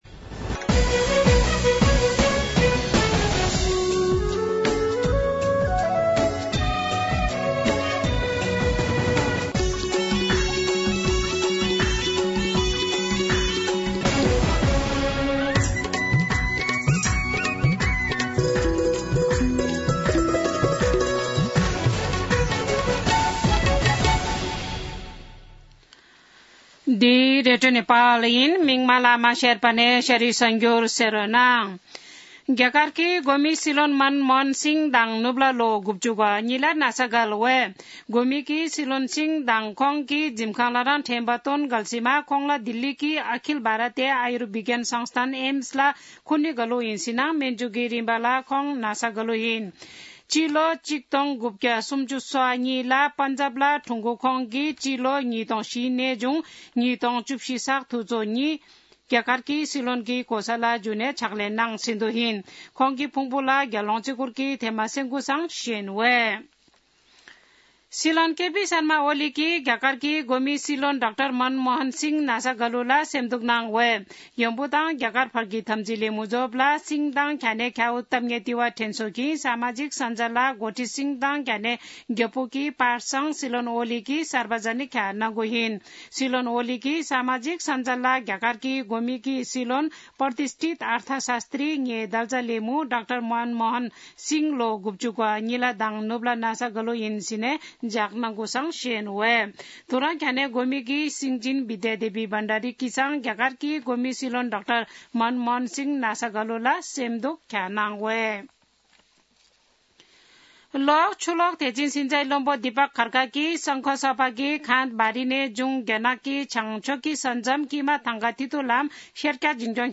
शेर्पा भाषाको समाचार : १३ पुष , २०८१
Sherpa-news-4.mp3